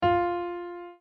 Фортепиано фа